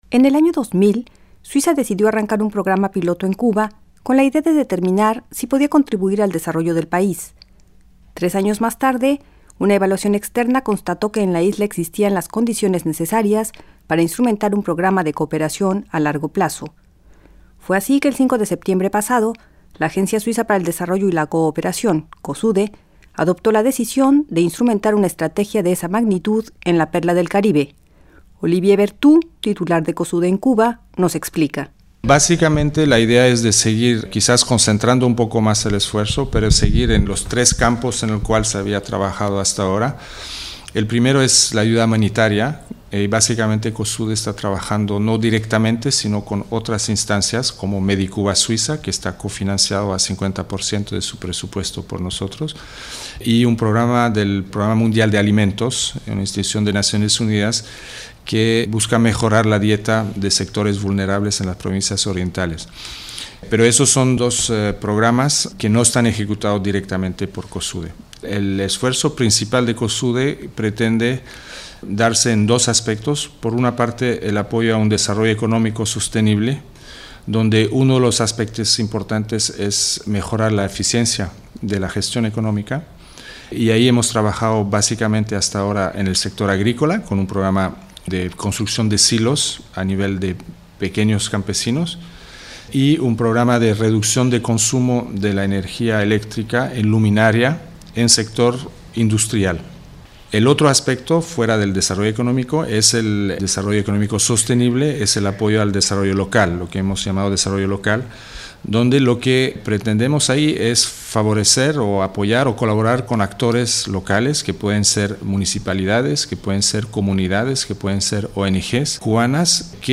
La Agencia Suiza para el Desarrollo y la Cooperación (COSUDE) inició este 2004 en Cuba un programa de largo plazo, tras un periodo piloto de tres años. Los representantes de la organización helvética y algunos de sus interlocutores locales hablan a swissinfo sobre ese trabajo conjunto.